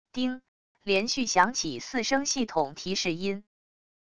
叮……连续响起四声系统提示音wav音频